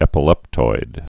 (ĕpə-lĕptoid)